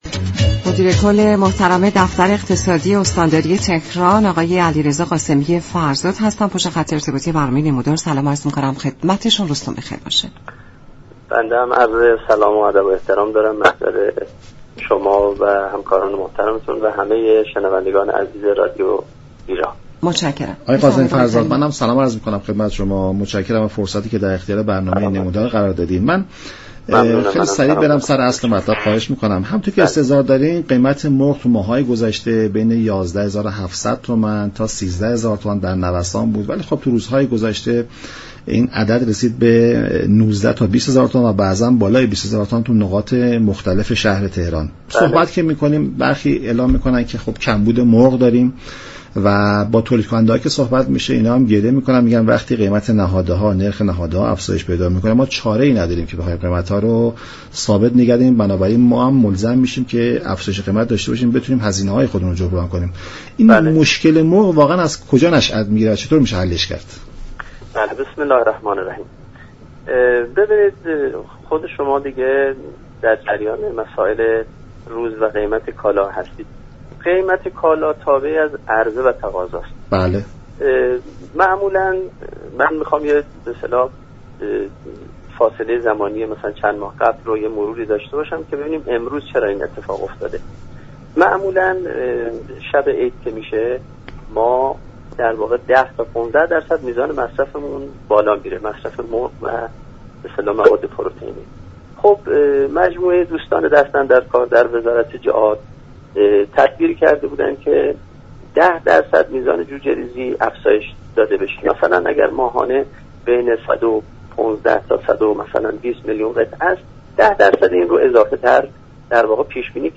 به گزارش شبكه رادیویی ایران، علیرضا قاسمی فرزاد مدیر كل دفتر اقتصادی استانداری تهران در برنامه «نمودار» درباره نوسانات قیمت مرغ گفت: شیوع ویروس كرونا در اسفند سال گذشته تعادل عرضه و تقاضا را از میان برد و در ماه های بعد باعث كاهش مرغ در بازار شد.